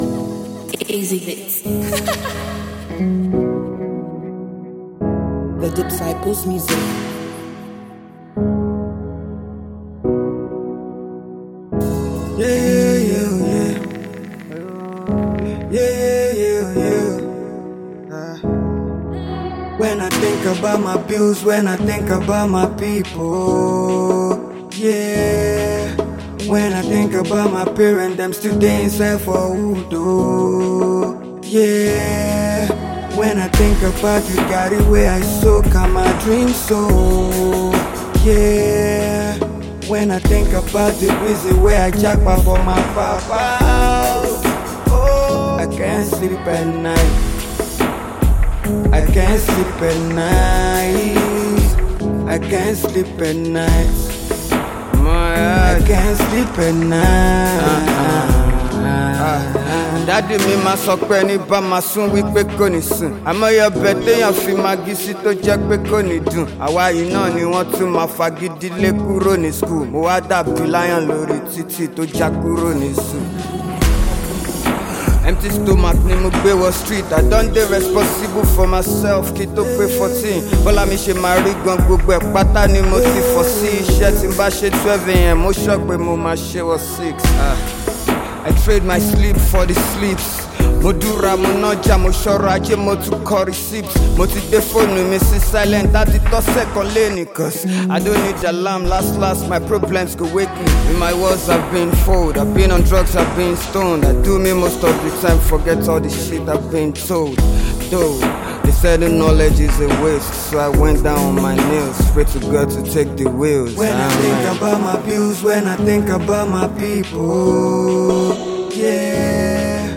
Noteworthy Nigerian rapper and performer
is an impressive song with a surprising tune